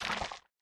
Minecraft Version Minecraft Version latest Latest Release | Latest Snapshot latest / assets / minecraft / sounds / block / sculk / spread3.ogg Compare With Compare With Latest Release | Latest Snapshot